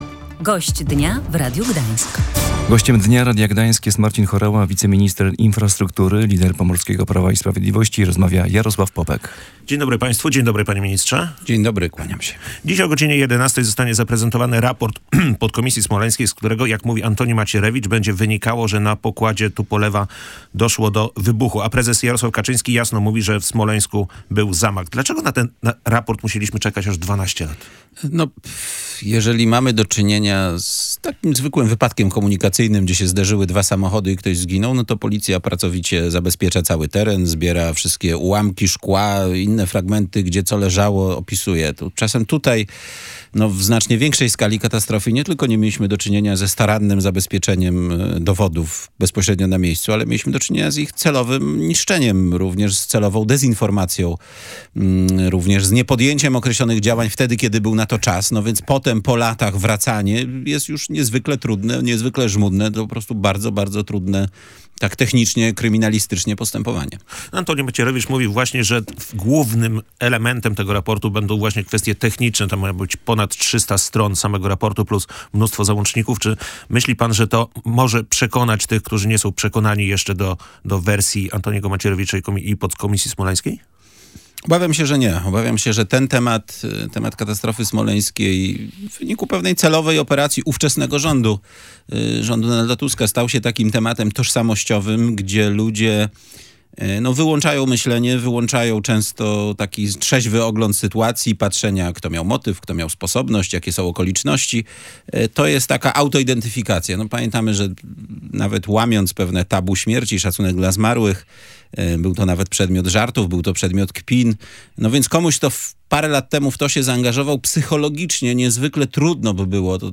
Podczas badania przyczyn katastrofy smoleńskiej od początku trzeba było przyjąć, że jedną z wersji może być zamach. Tego nie zrobiono – mówił w Radiu Gdańsk wiceminister infrastruktury Marcin Horała.